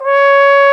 Index of /90_sSampleCDs/Roland L-CDX-03 Disk 2/BRS_Trombone/BRS_Tenor Bone 4